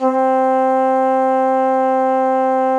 FluteClean2_C2.wav